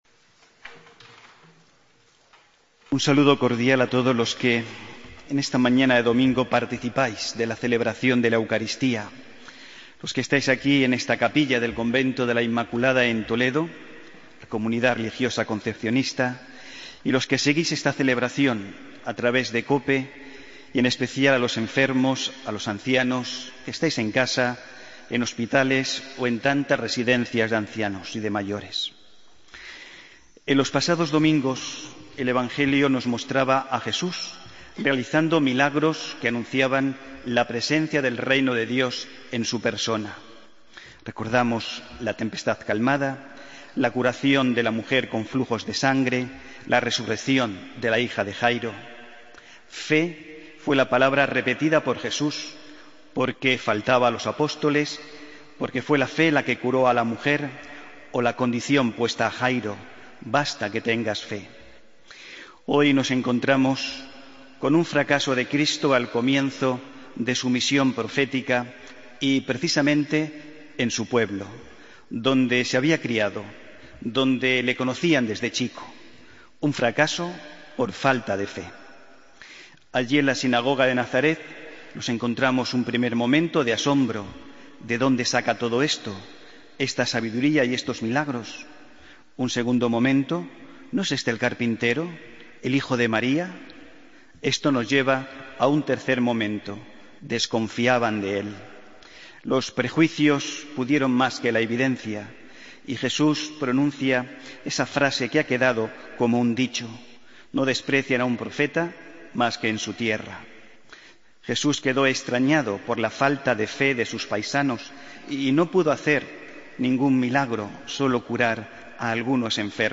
Homilía, domingo 5 de julio de 2015